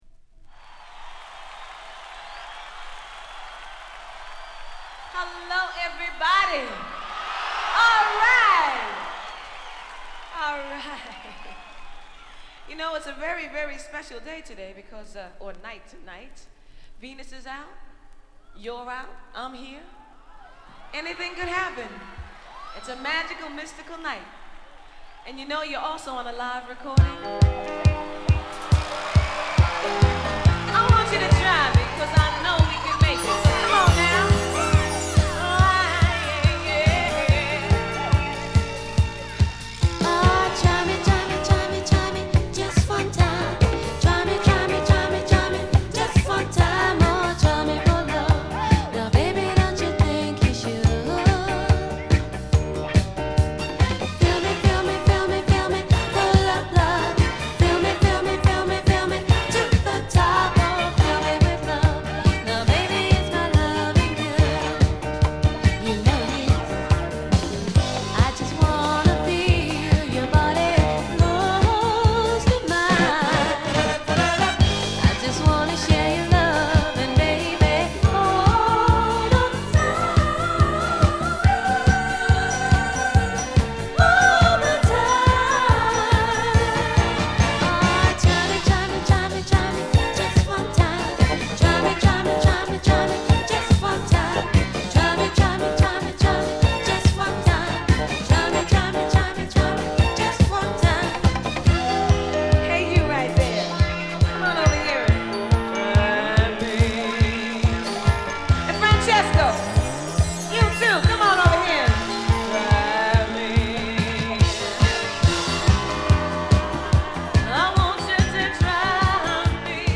L.A.でのライブレコーディングとスタジオレコーディングを併せた作で